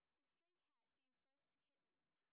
sp16_street_snr20.wav